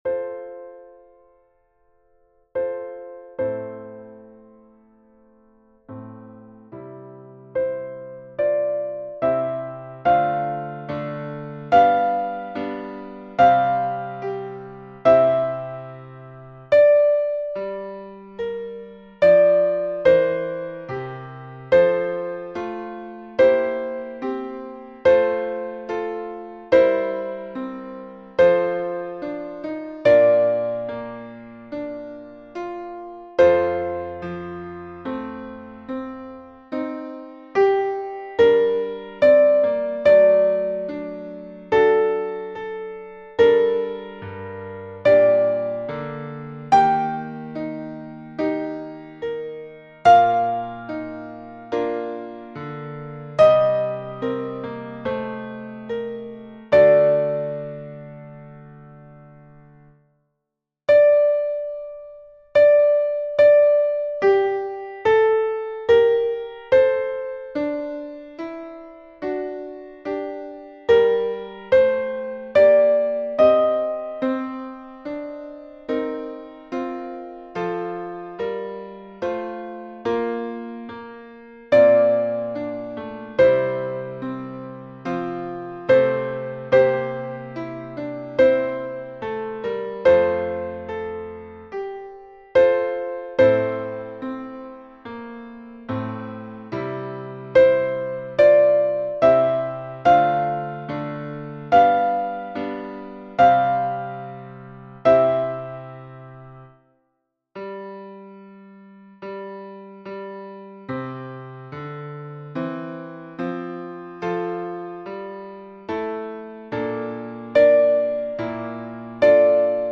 Soprano (piano)